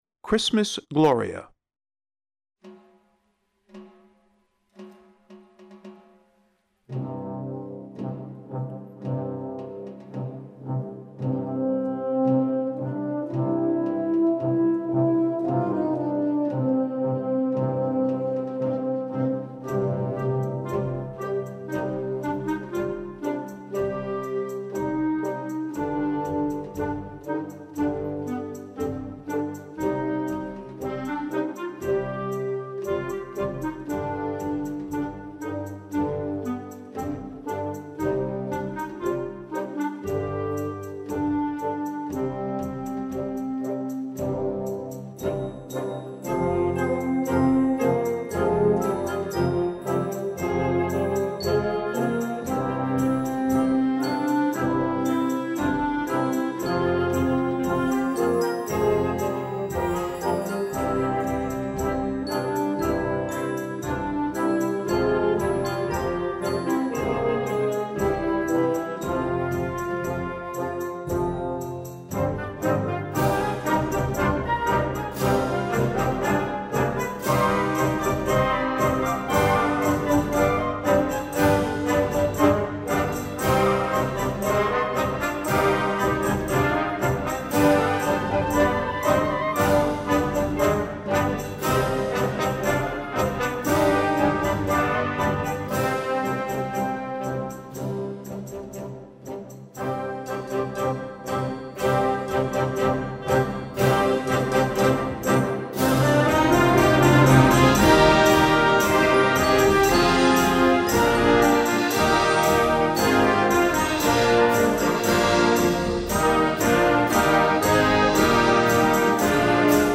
Besetzung: Blasorchester
The arrangement builds to a climax